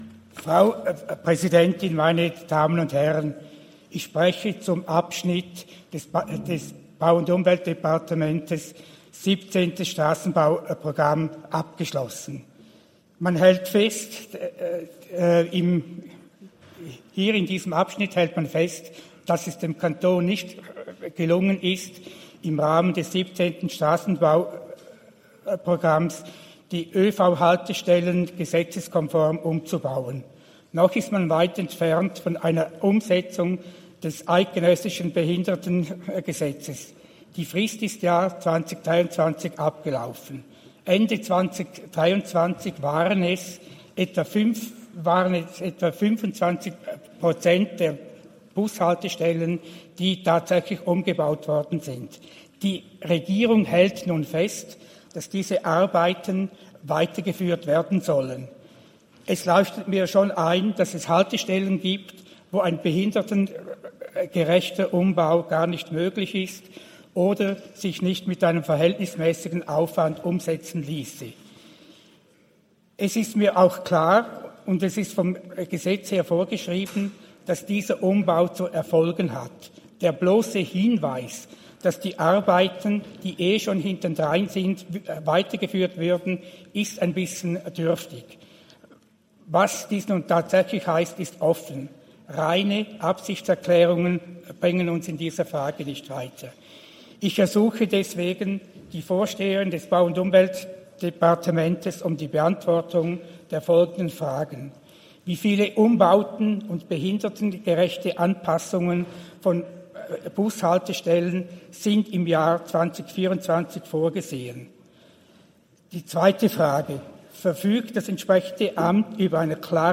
Session des Kantonsrates vom 3. und 4. Juni 2024, Sommersession
3.6.2024Wortmeldung